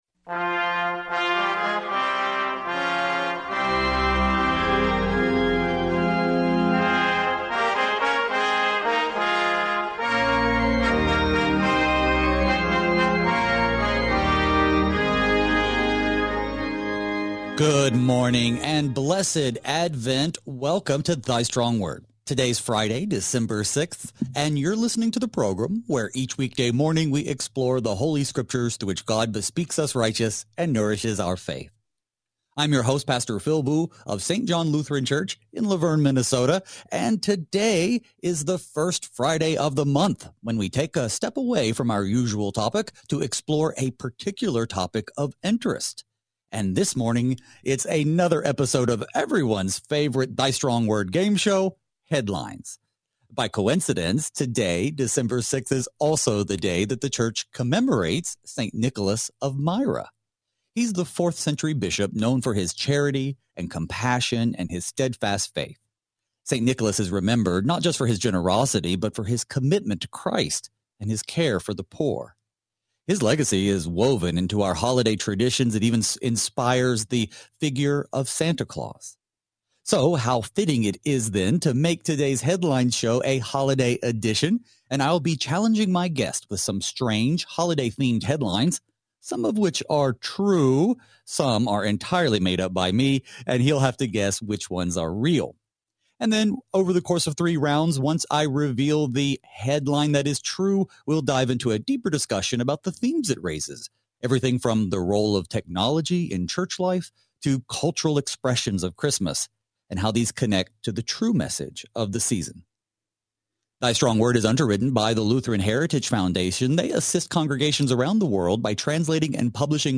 Thy Strong Word reveals the light of our salvation in Christ through study of God’s Word, breaking our darkness with His redeeming light. Each weekday, two pastors fix our eyes on Jesus by considering Holy Scripture, verse by verse, in order to be strengthened in the Word and be equipped to faithfully serve in our daily vocations.